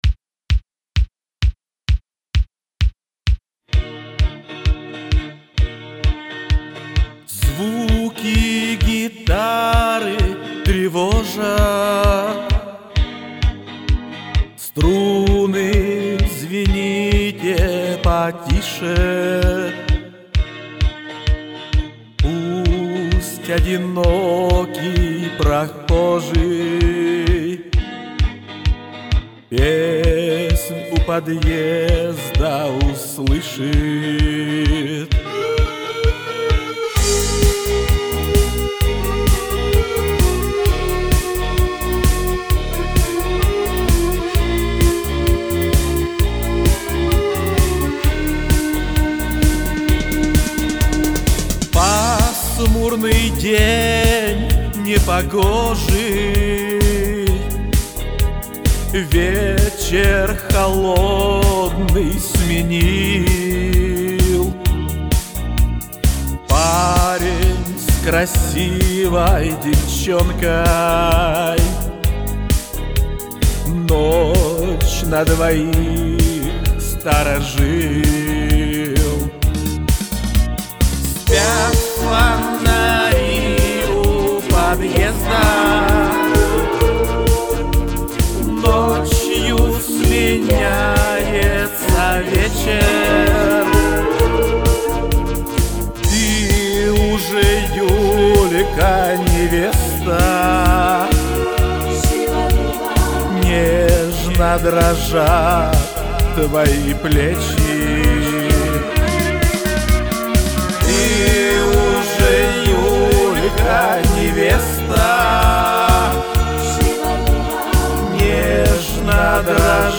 Дворовые Песни Шансона
.Очень нравится эта дворовая песенка.